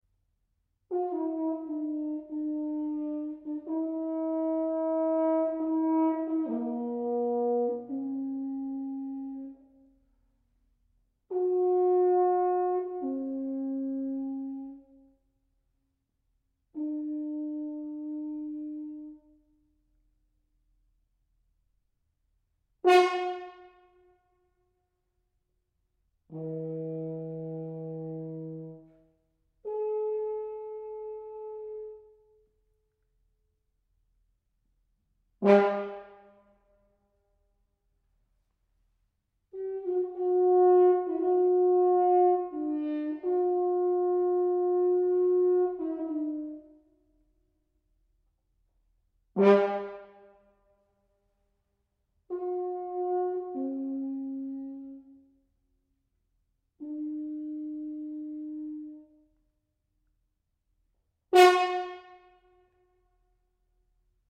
UK based natural hornist